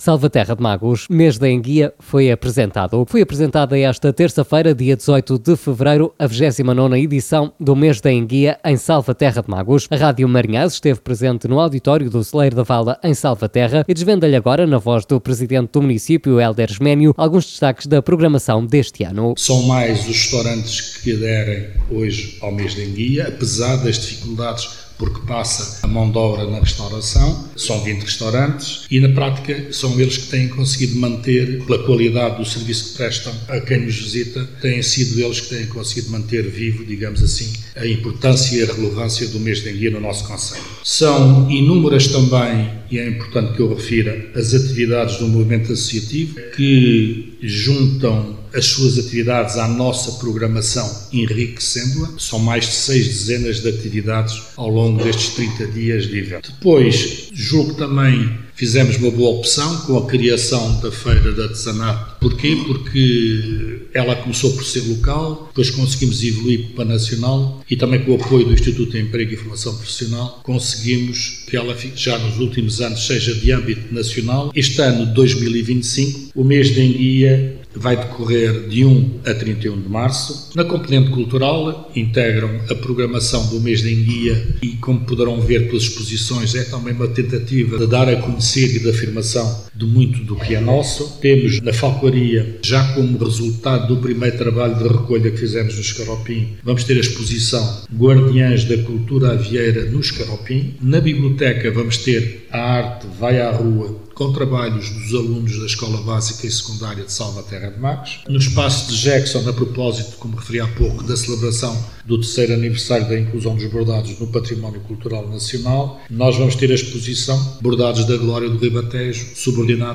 O auditório do Cais da Vala, em Salvaterra de Magos, foi pequeno demais para todos aqueles que quiseram assistir, esta terça-feira, dia 18 de fevereiro, à apresentação oficial da 29.ª edição do Mês da Enguia, uma iniciativa centrada naquelas que são as denominadas “Rainhas do Tejo”, mas que, ao longo do tempo, se tem destacado pela panóplia de iniciativas desportivas e culturais que lhe estão associadas.
Escute, aqui, a intervenção de Hélder Manuel Esménio durante a apresentação da 29.ª edição do Mês da Enguia: